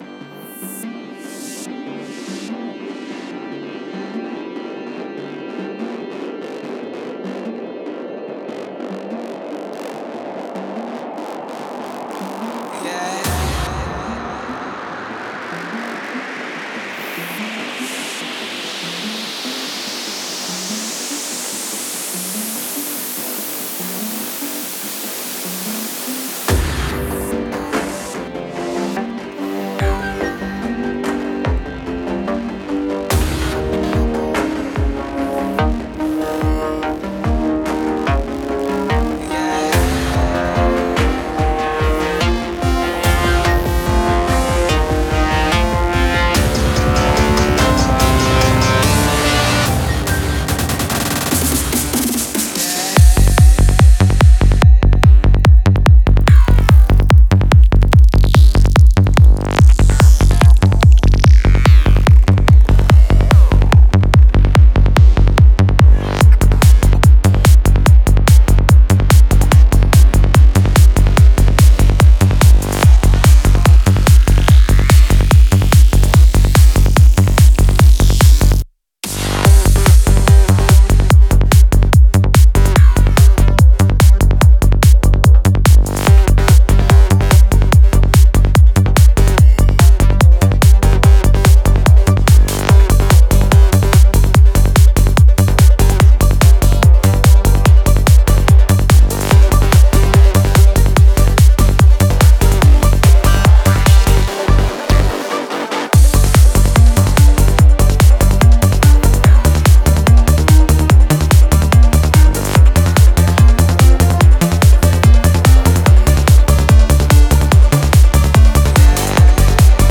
Genre: Goa, Psychedelic Trance.